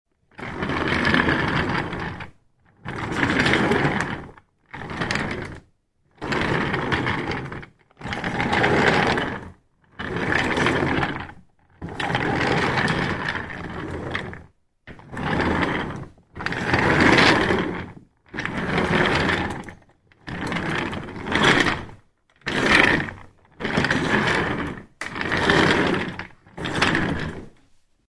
Звуки стульев
Скрип офисного кресла на роликах при перемещении по паркетному полу